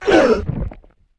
Index of /App/sound/monster/misterious_diseased_spear